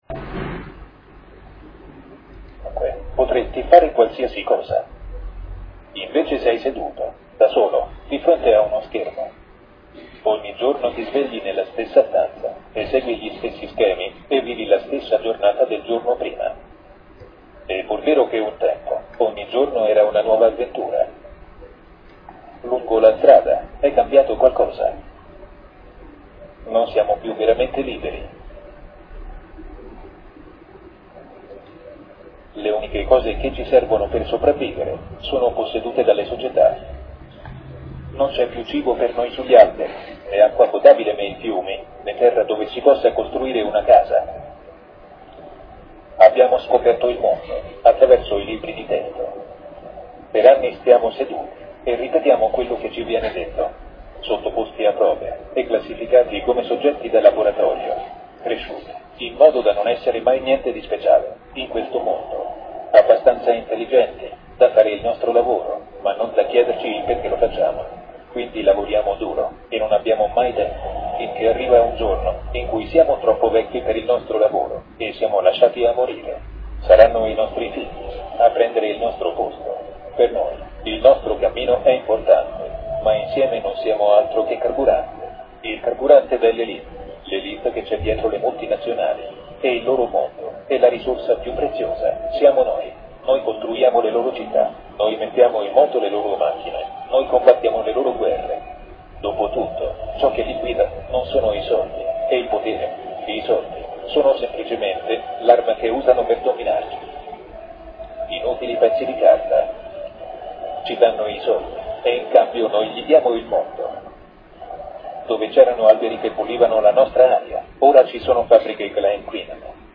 con una durata di 9', ho trascurato le immagini di fondo, poichè non indispensabili alla comprensione del contenuto verbale, ne ho registrato l'audio (anche se un po' disturbato da qualche colonna sonora e creato per sintesi vocale), l'ho riportato in mp3 compresso ed ecco che l'intero contenuto finisce per occupare meno di 1 Mega.